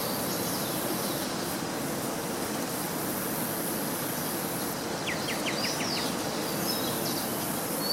Caneleiro-preto (Pachyramphus polychopterus)
Nome em Inglês: White-winged Becard
Fase da vida: Adulto
Localidade ou área protegida: Reserva Natural del Pilar
Condição: Selvagem
Certeza: Gravado Vocal
anambe-comun.mp3